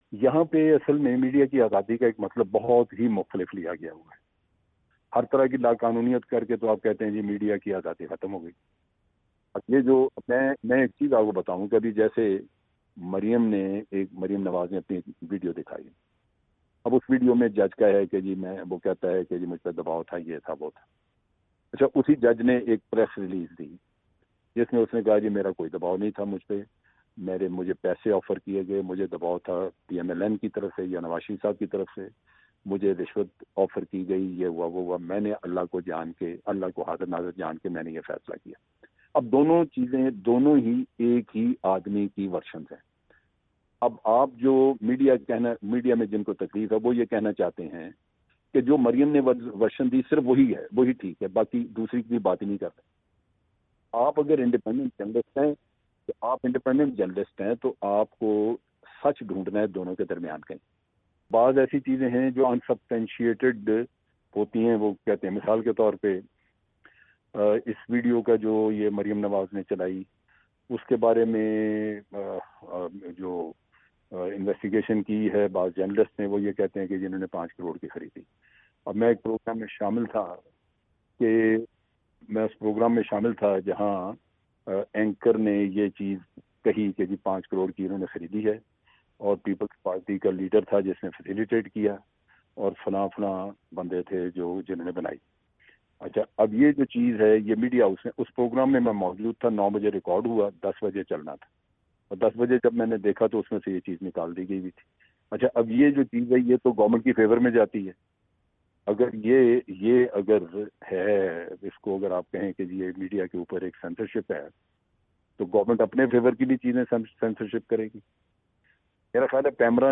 آج دفاعی تجزیہ کار لیفٹیننٹ جنرل ریٹائرڈ امجد شعیب کا انٹرویو پیش کیا جا رہا ہے۔